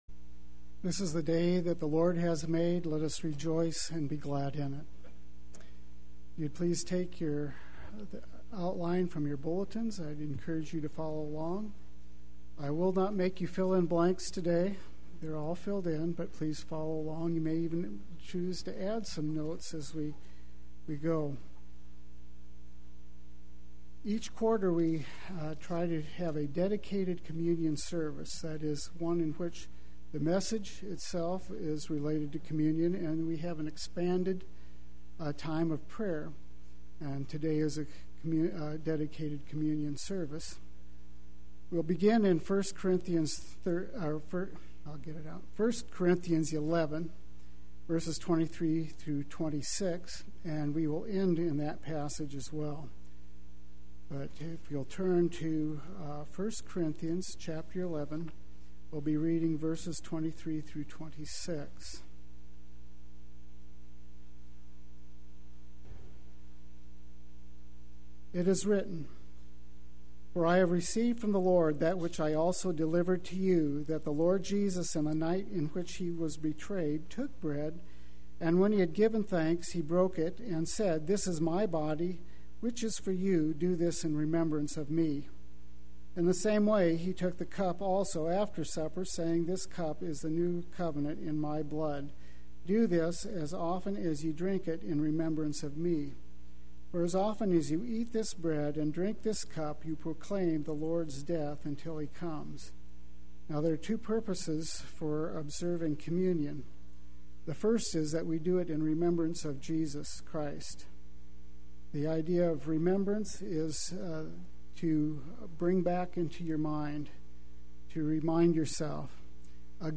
Until He Comes (Communion Service)
Play Sermon Get HCF Teaching Automatically.